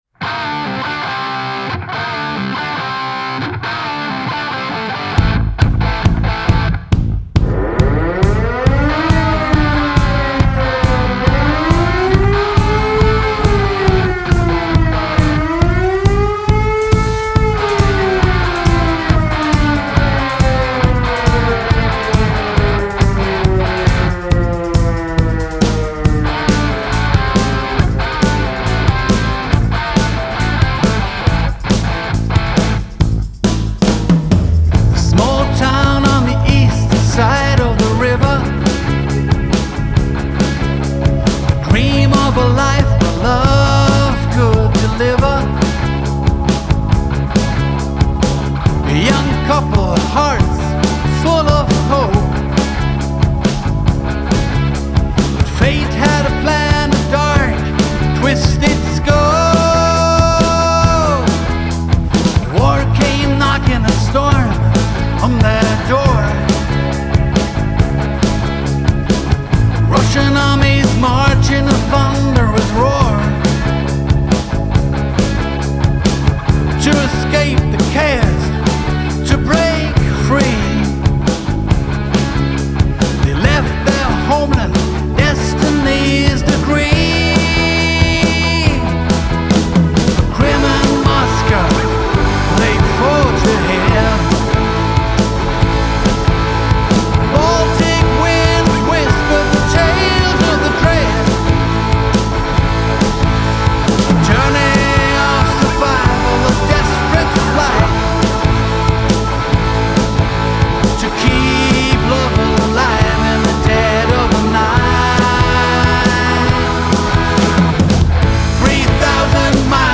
Mai 2024, ist eine Rock-EP mit drei Songs.